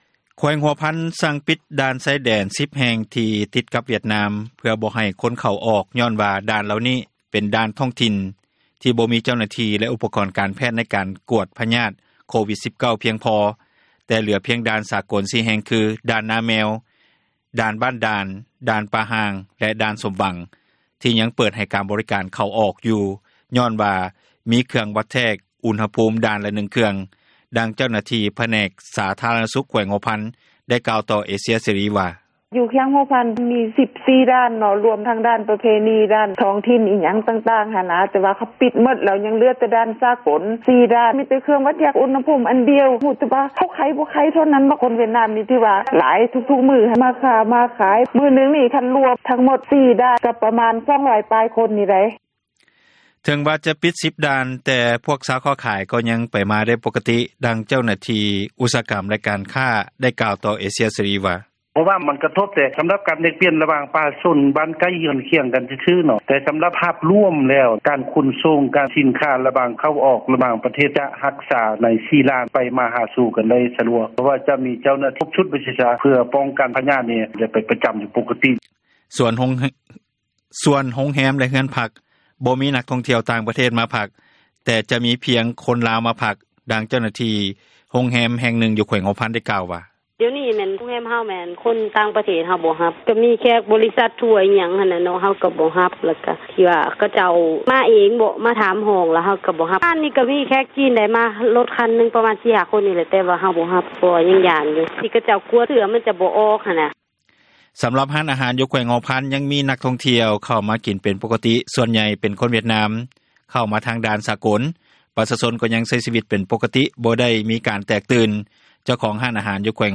ເຈົ້າໜ້າທີ່ຜແນກ ສາທາຣະນະສຸຂ ແຂວງຫົວພັນ ໄດ້ກ່າວຕໍ່ເອເຊັຽເສຣີ ວ່າ: